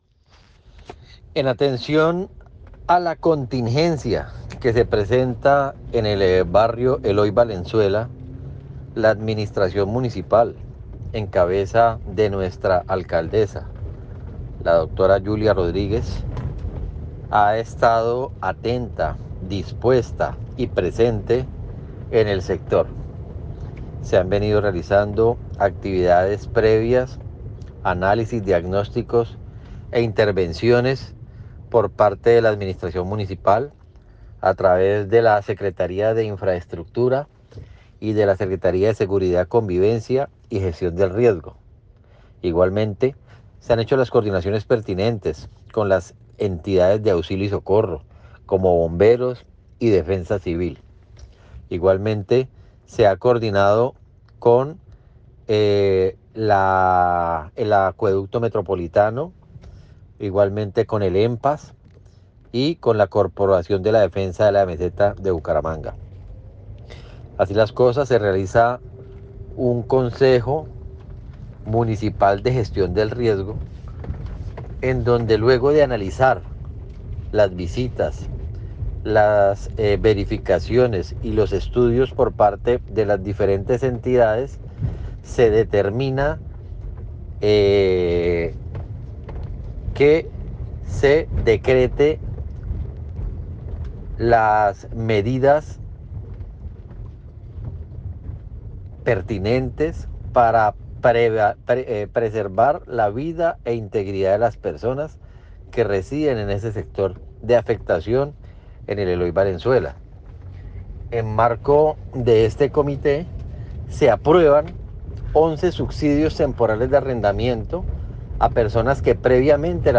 Juan Carlos Pinto, Secretario de Seguridad manifestó: “se caracterizaron 11 núcleos familiares que requieren evacuación preventiva y a quienes se les brindará la ayuda de subsidio, igualmente estaremos al tanto con todas las organizaciones encargadas para garantizar la seguridad de los gironeses del sector”.
Juan Carlos Pinto, Secretario de Seguridad.mp3